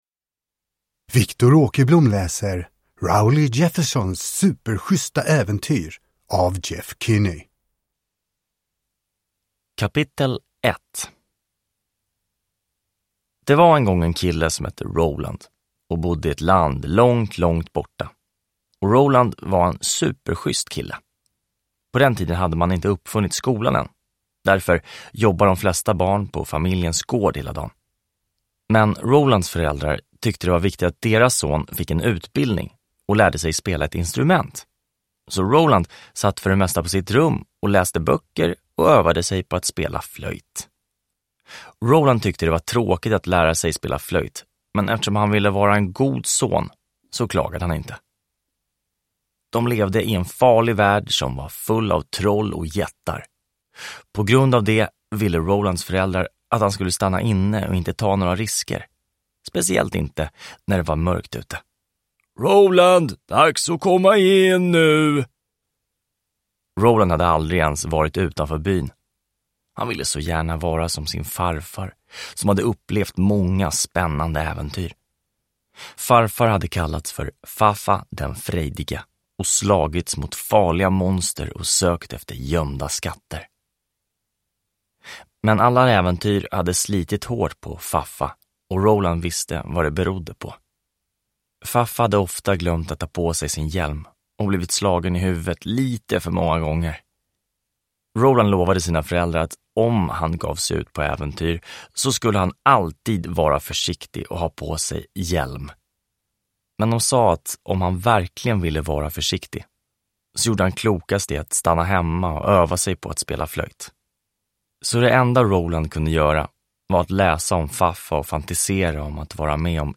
Rowley Jeffersons superschyssta äventyr – Ljudbok – Laddas ner